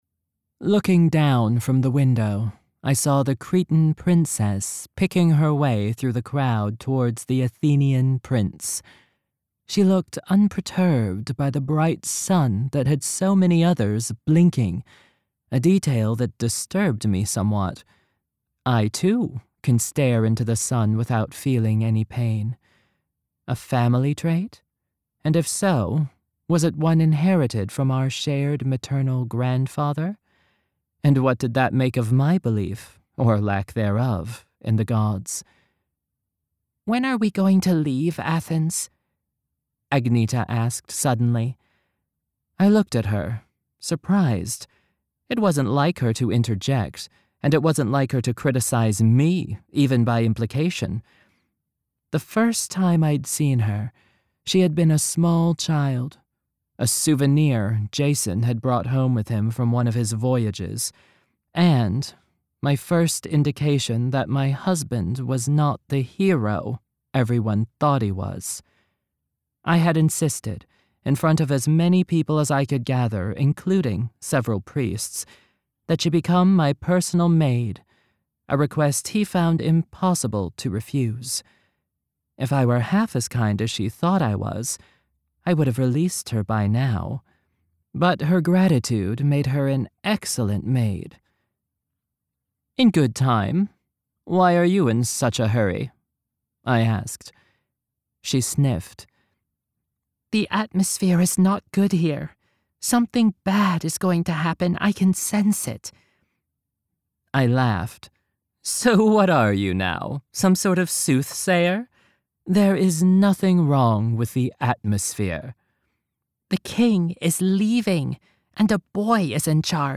Narration Samples
1st Person
Older woman & teenage girl